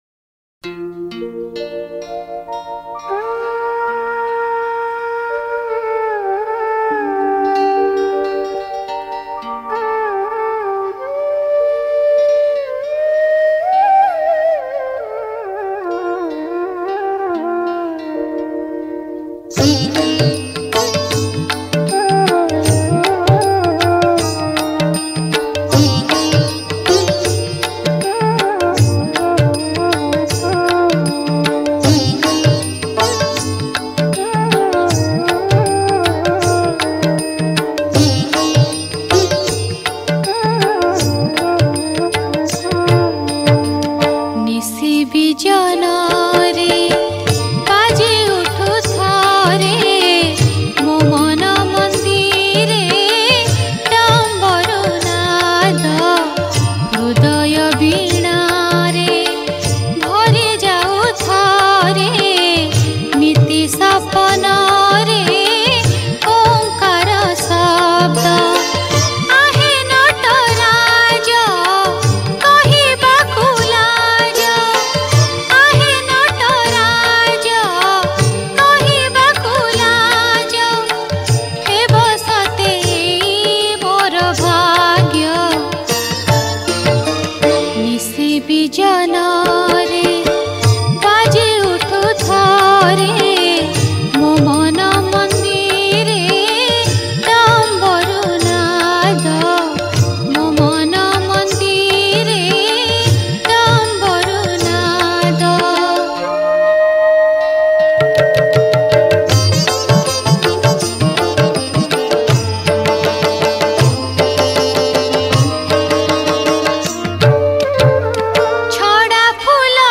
Jagara Special Odia Bhajan Song